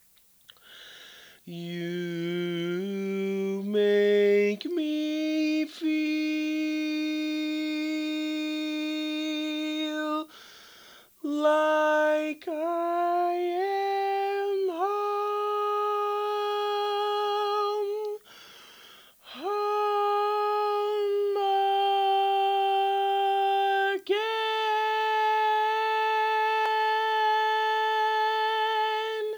Key written in: F Major
Type: Barbershop
Each recording below is single part only.